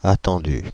Ääntäminen
IPA : /ɪksˈpɛktɪd/